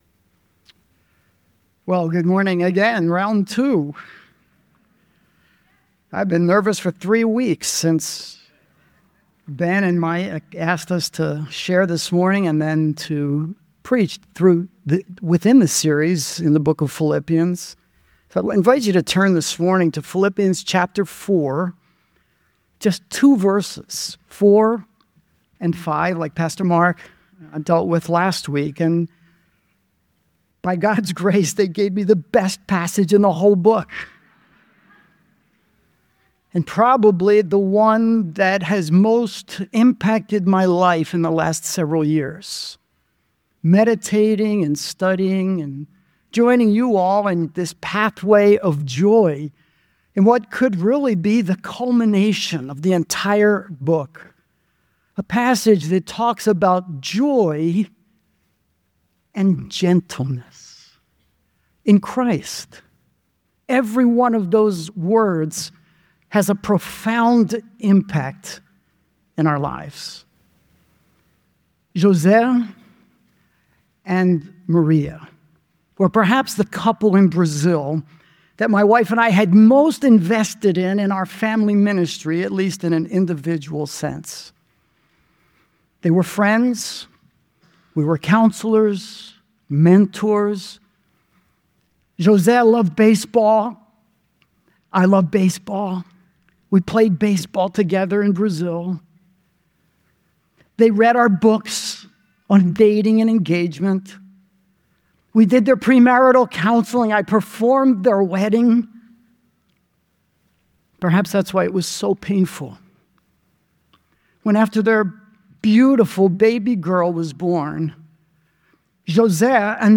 Visiting missionary